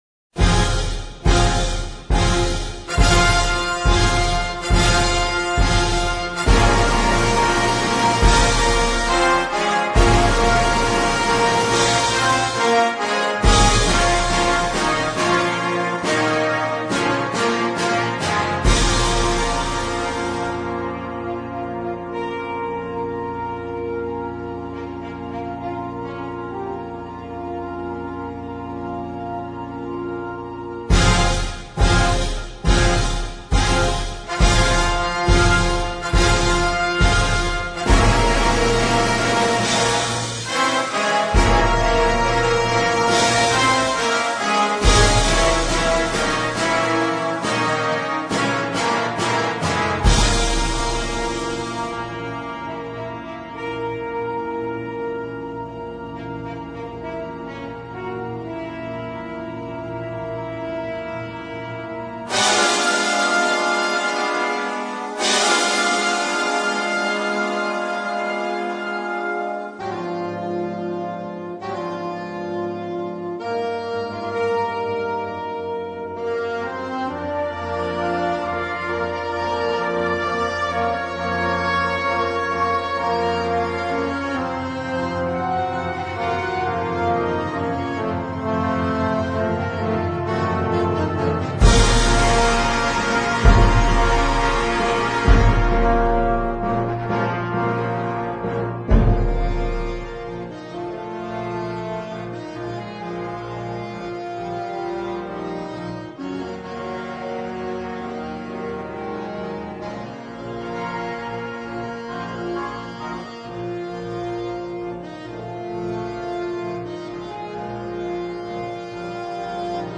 composizione per banda